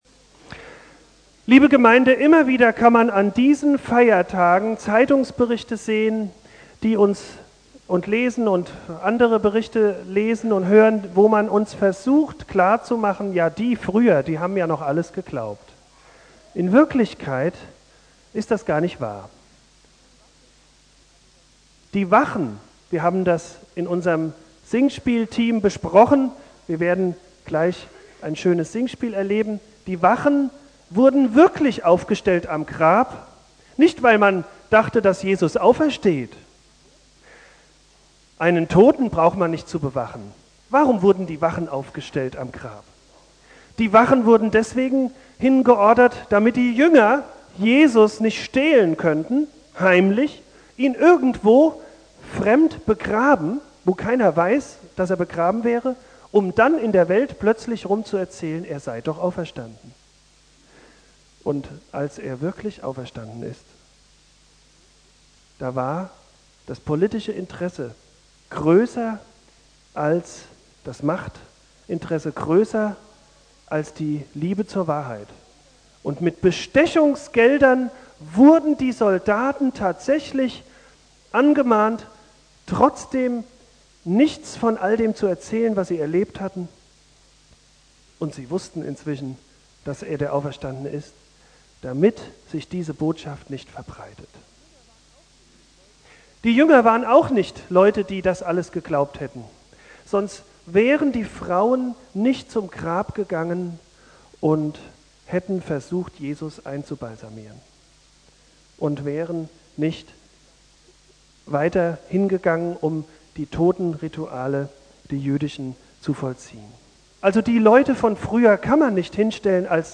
Ostersonntag
Thema: Kurzpredigt im Rahmen eines Ostersingspieles